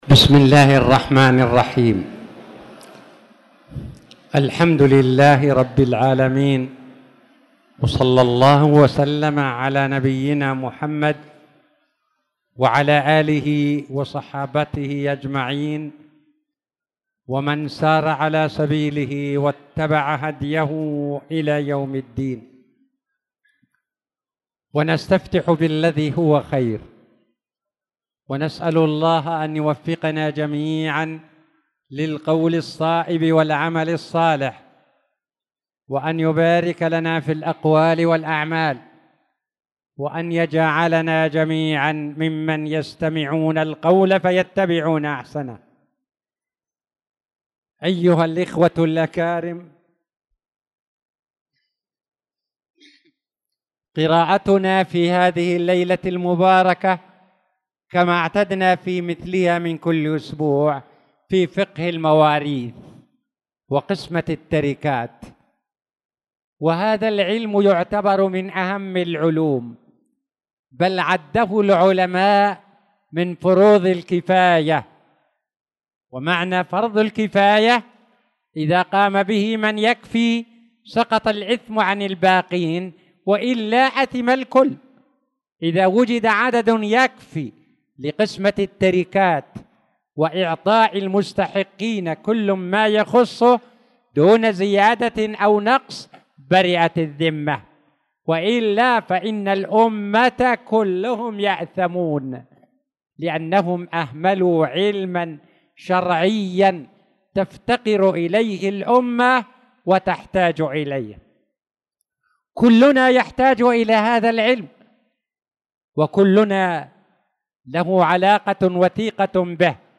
تاريخ النشر ١٦ شعبان ١٤٣٧ هـ المكان: المسجد الحرام الشيخ